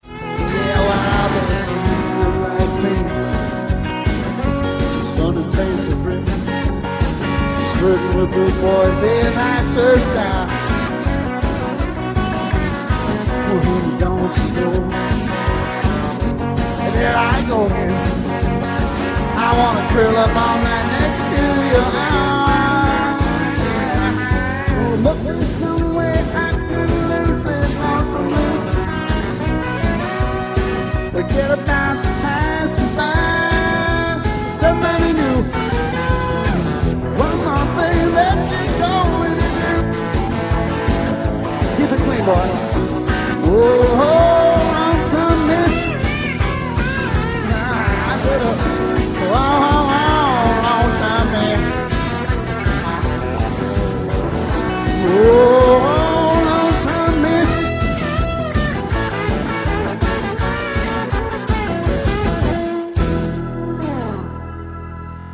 * * *Country Rock* * * live guitar and vocals